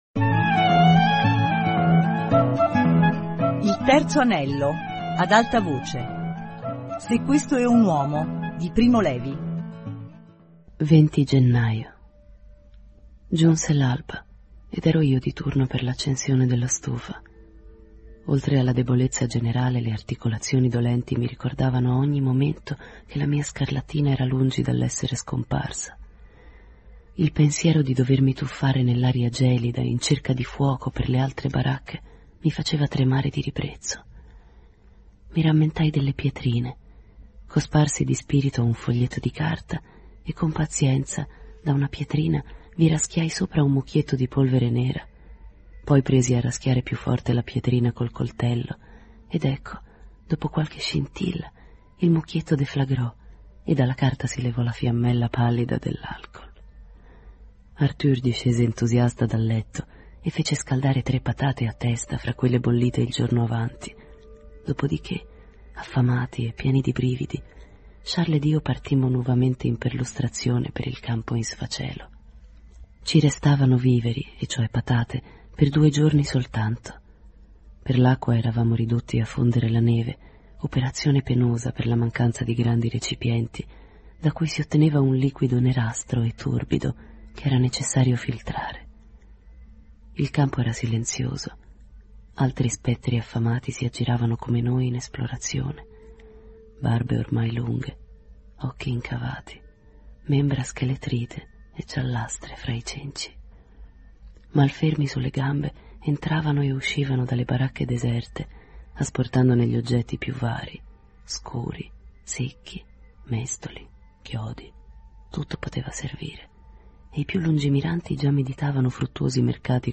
Se questo é un uomo - Lettura XXI